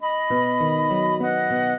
flute-harp
minuet8-2.wav